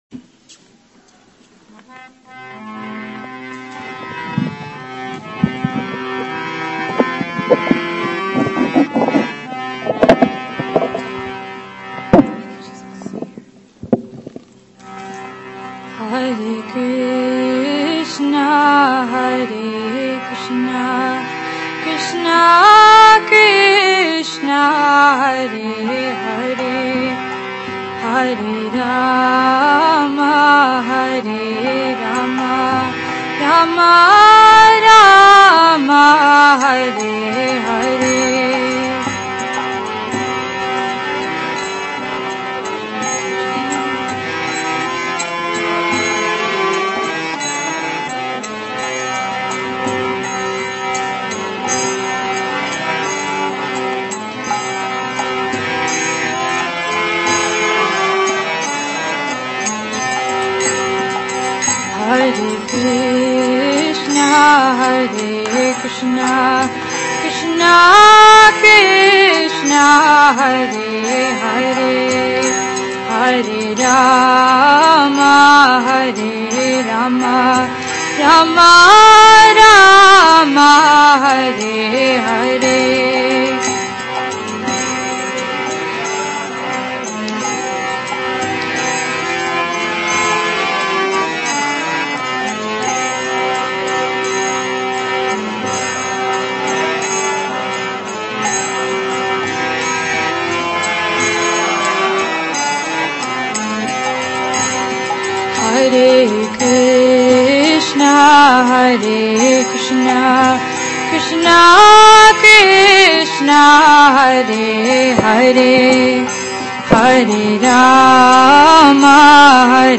Hare Krsna Kirtana by SoulConnection